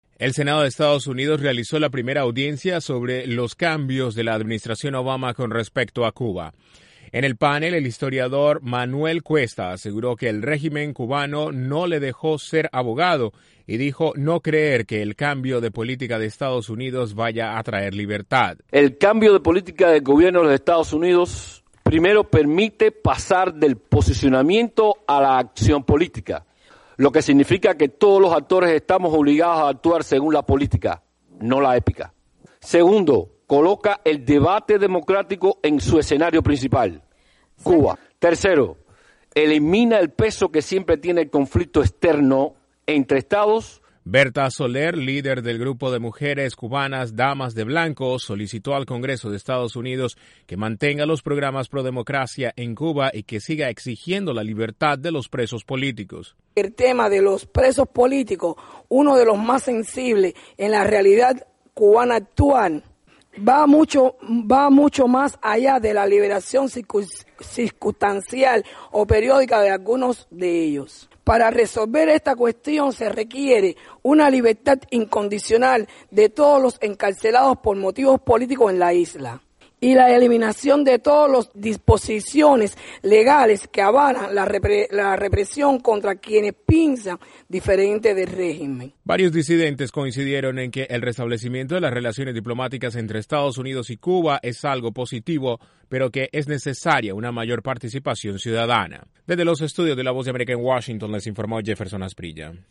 En una audiencia del Senado estadounidense activistas coincidieron en que el restablecimiento de relaciones diplomáticas entre EE.UU. y Cuba es algo positivo, pero es necesaria mayor participación ciudadana. Desde la Voz de América en Washington informa